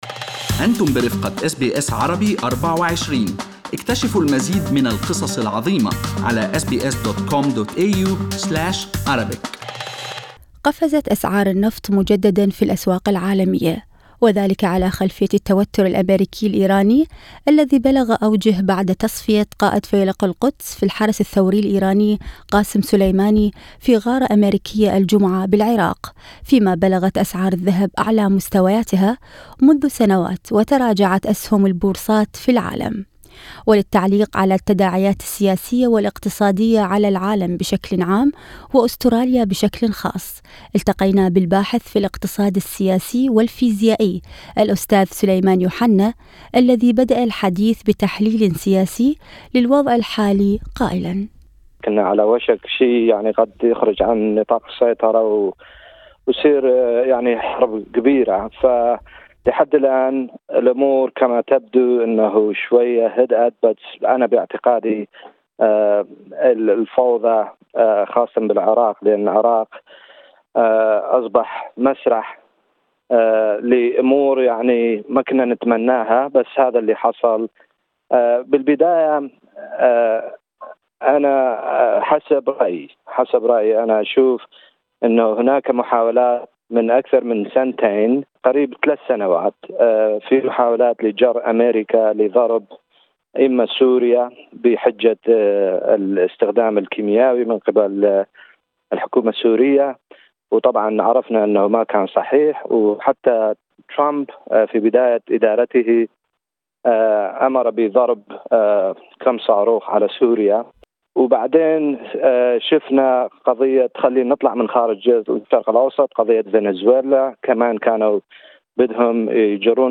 التقينا بالباحث في الإقتصاد السياسي والفيزيائي